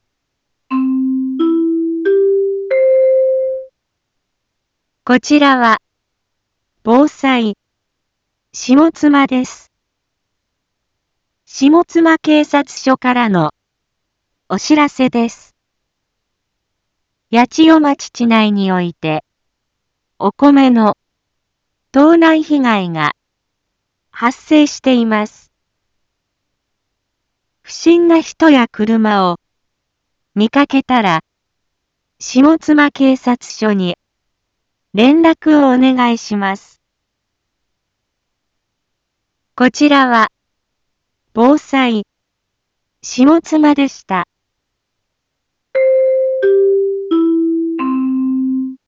一般放送情報